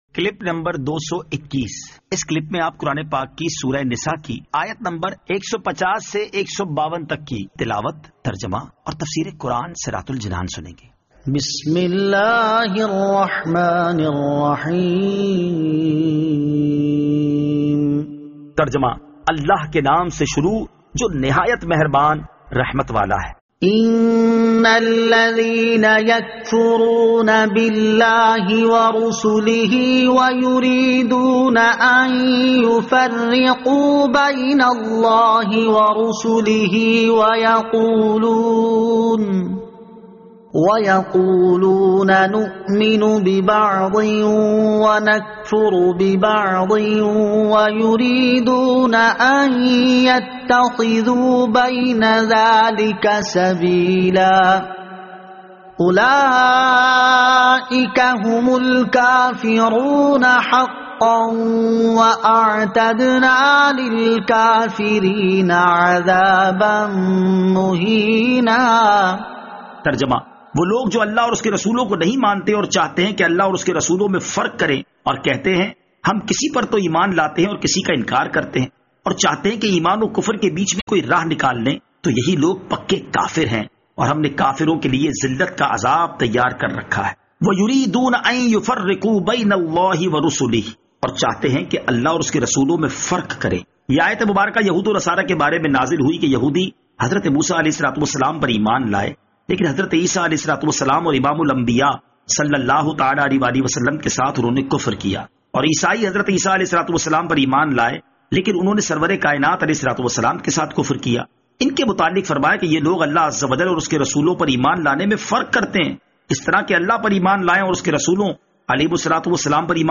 Surah An-Nisa Ayat 150 To 152 Tilawat , Tarjama , Tafseer